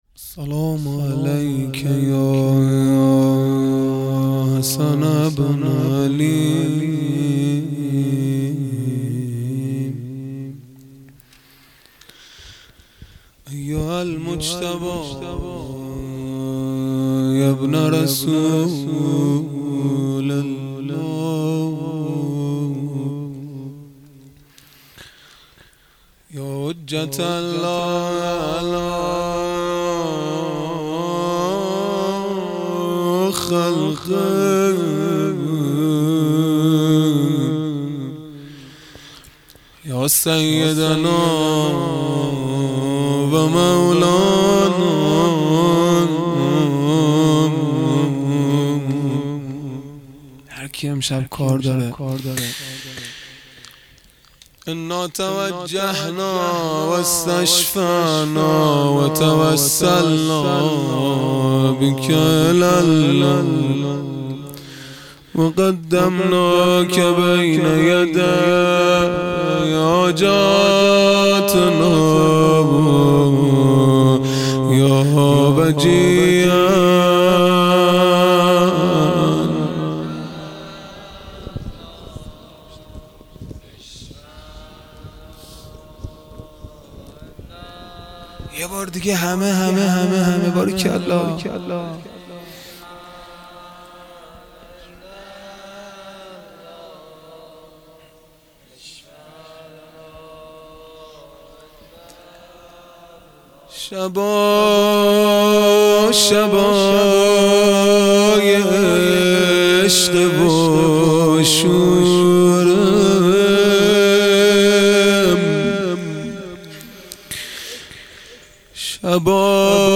خیمه گاه - هیئت بچه های فاطمه (س) - روضه | شبا شبای عشق و شوره | شنبه 23 مرداد 1400